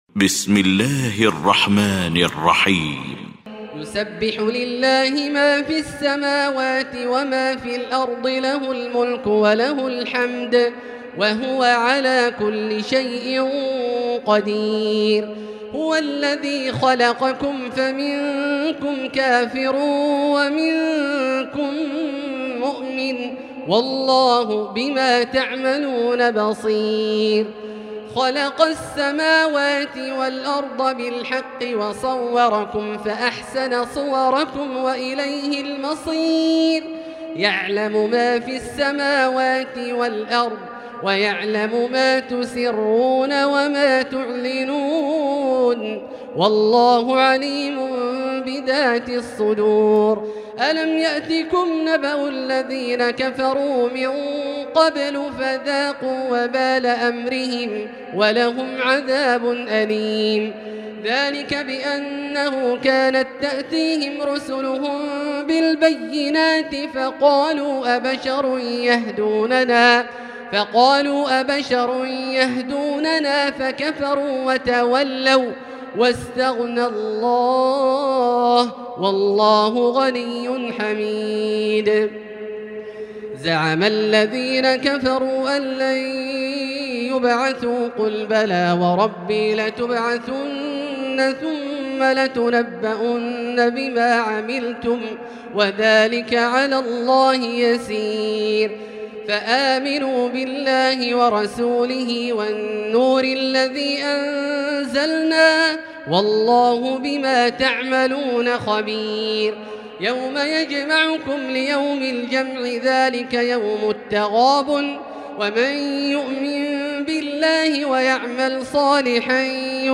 المكان: المسجد الحرام الشيخ: فضيلة الشيخ عبدالله الجهني فضيلة الشيخ عبدالله الجهني التغابن